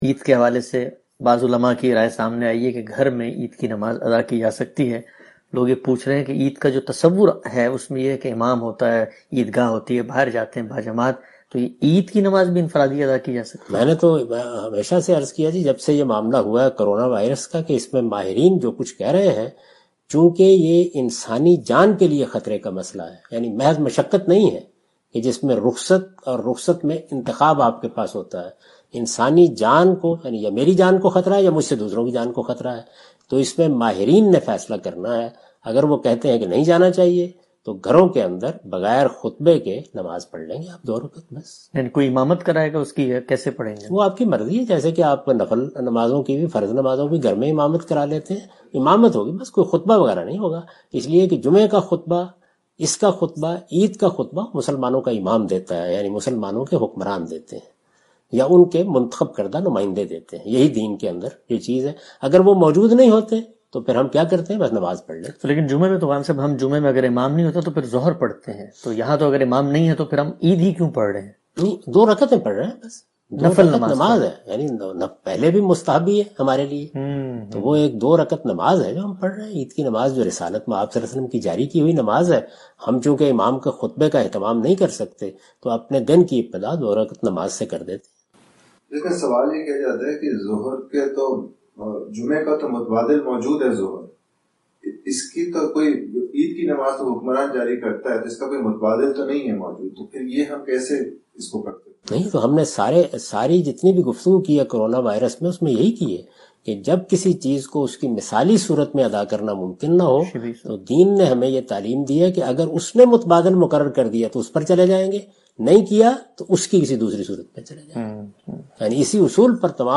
Category: Reflections / Questions_Answers /
In this video Javed Ahmad Ghamidi answer the question about "How to offer eid prayer in current circumstances?".